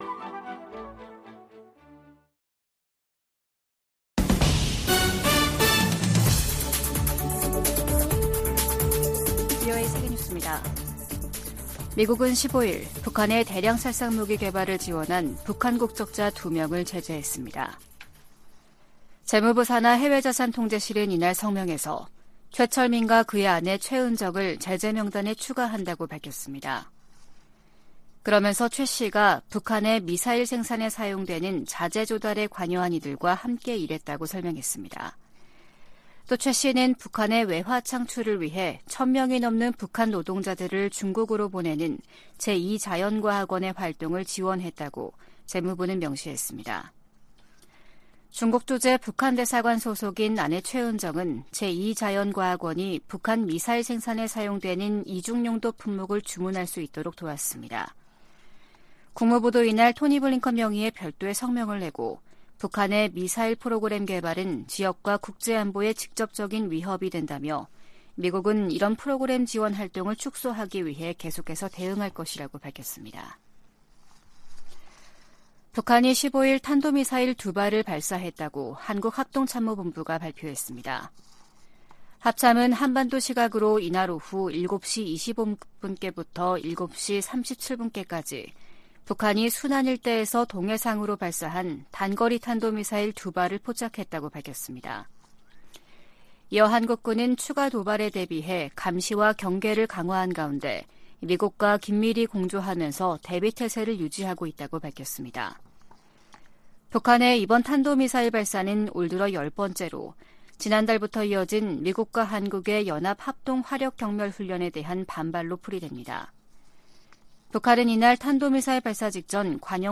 VOA 한국어 아침 뉴스 프로그램 '워싱턴 뉴스 광장' 2023년 6월 16일 방송입니다. 미국은 심해지는 중국과의 경쟁을 관리하기 위해 한국, 일본 등과 동맹을 강화하고 있다고 미국 정부 고위 관리들이 밝혔습니다. 미국 하원 세출위원회 국방 소위원회는 2024회계연도 예산안에서 미군 유해 수습과 신원 확인 외에는 어떤 대북 관련 지원도 할수 없도록 했습니다.